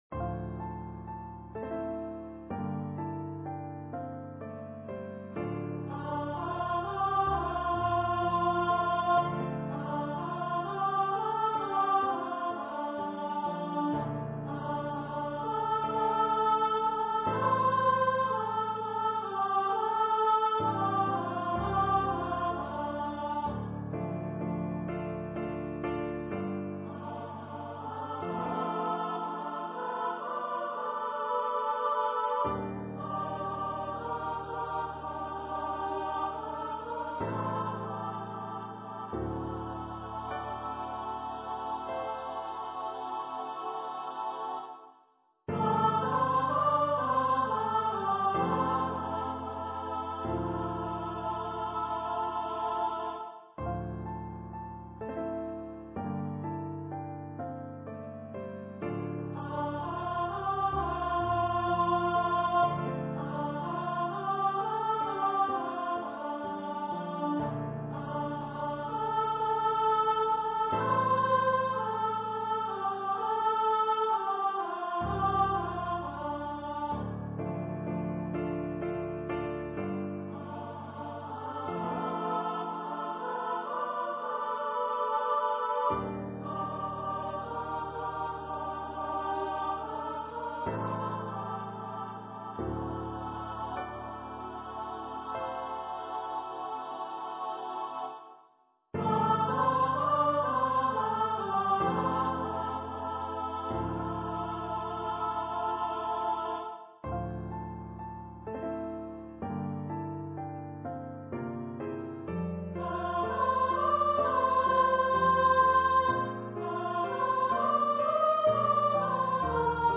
for SSA choir
Choir - 3 part upper voices